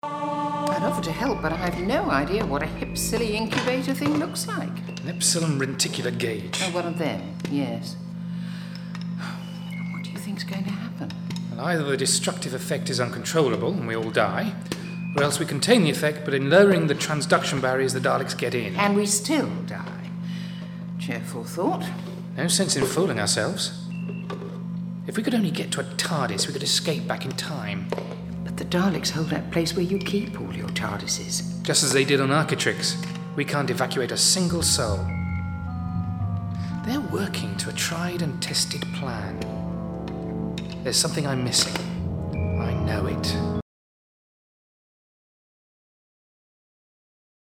Drama